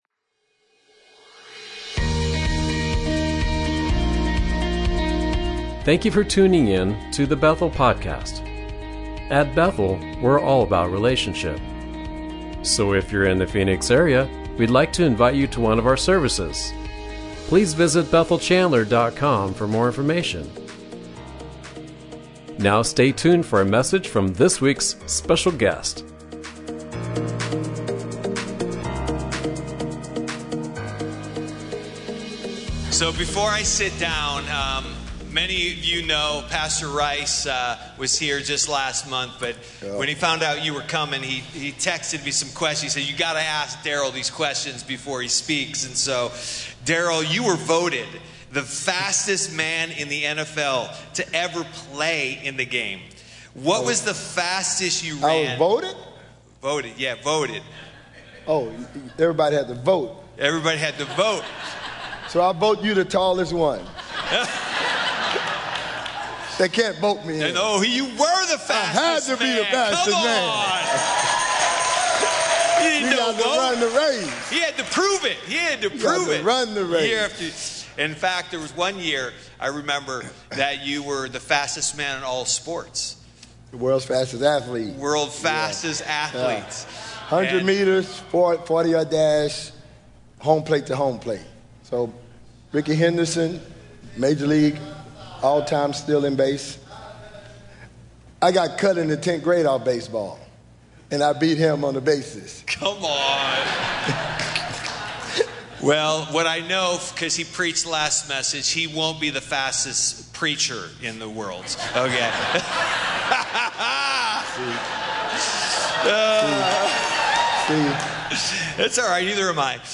Guest: Darrell Green